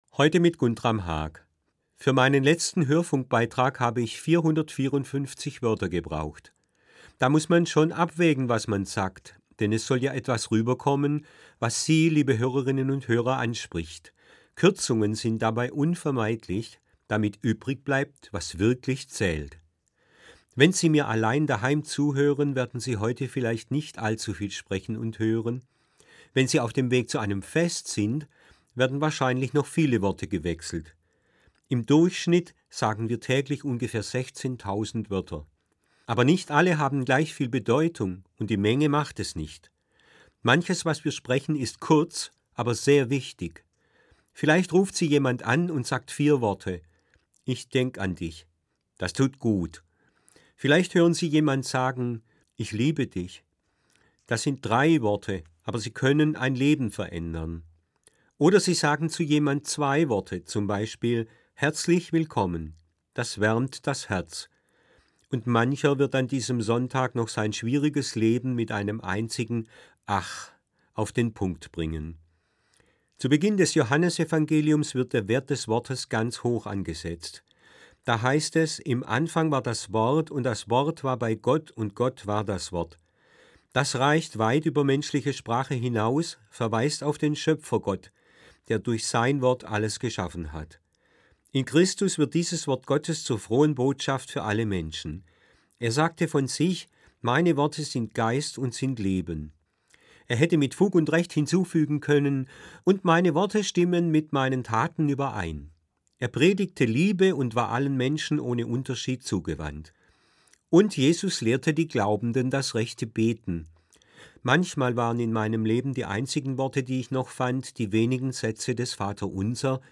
An jedem vierten Sonntag im Monat verantwortet die Gebietskirche Süddeutschland eine Sendung im Hörfunkprogramm des Südwestrundfunks: Sie wird im Magazin aus Religion, Kirche und Gesellschaft „SWR1 Sonntagmorgen“ ausgestrahlt, jeweils um 7:27 Uhr (Verkündigungssendung mit 2,5 Minuten Sendezeit).